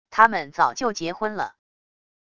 他们早就结婚了wav音频生成系统WAV Audio Player